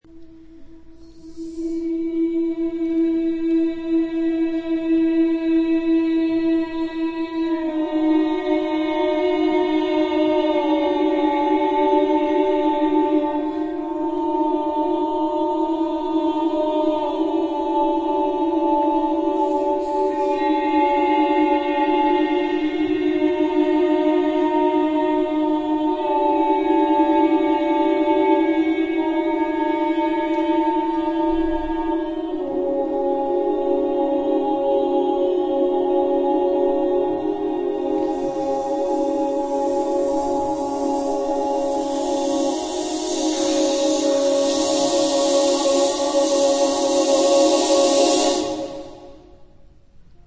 Genre-Style-Form: Vocal piece ; Secular
Type of Choir: 8S-8A  (16 women voices )
Tonality: clusters ; free tonality
Consultable under : 20ème Profane Acappella